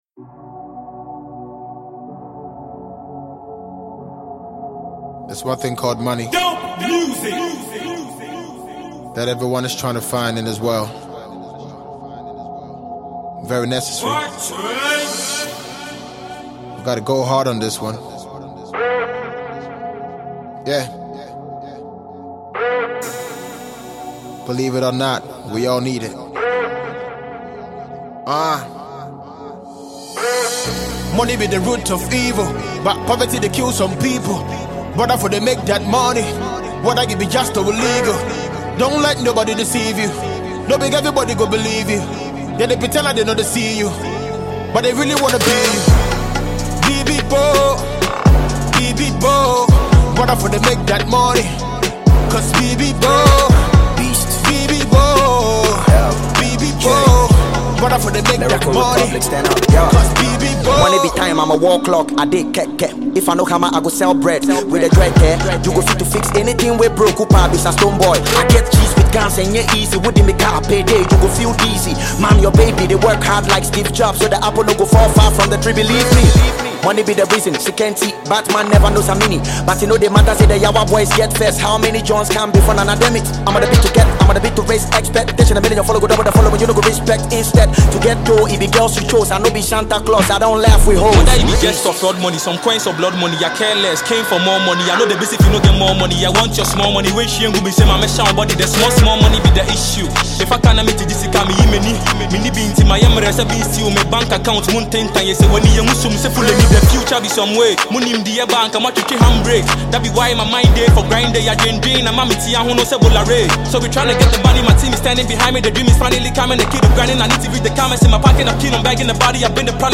Ghanaian multiple award winning rapper
Hip Hop single